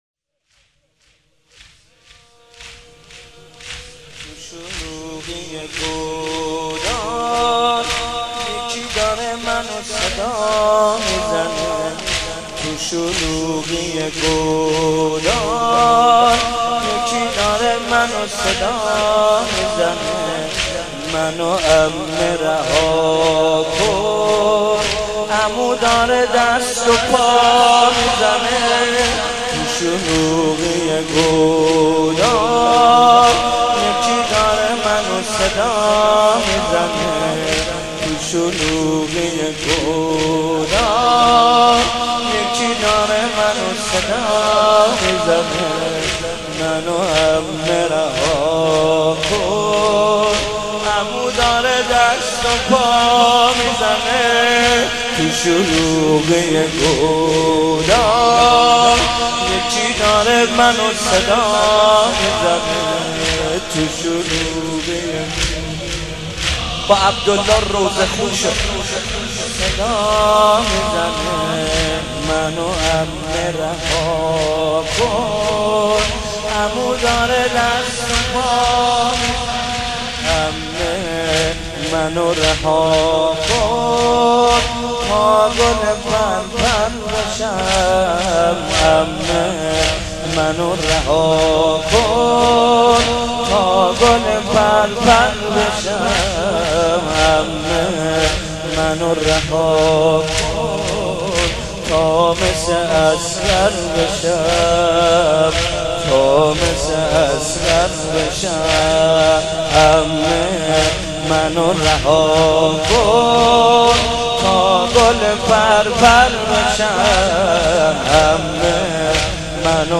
شب سوم رمضان 95، حاح محمدرضا طاهری
واحد، زمینه